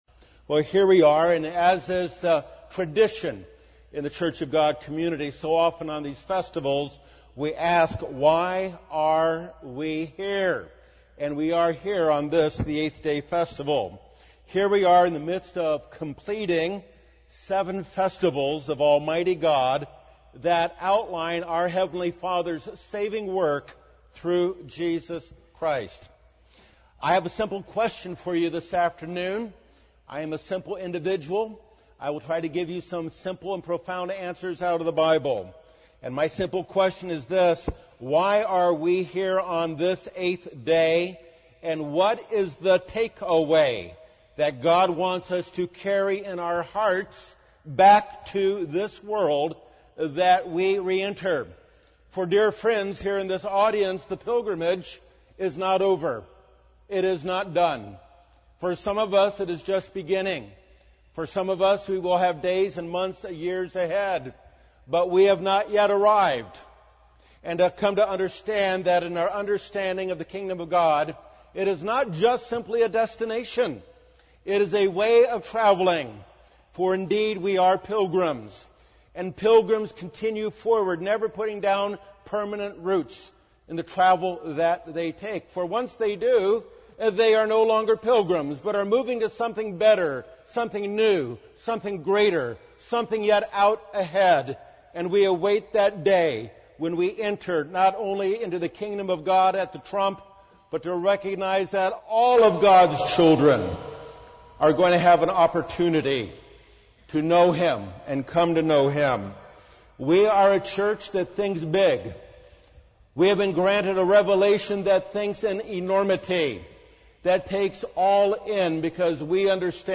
This sermon was given at the Oceanside, California 2016 Feast site.